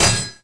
equip_metal_weapon.wav